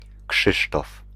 PronunciationPolish: [ˈkʂɨʂtɔf]
Pl-Krzysztof.ogg.mp3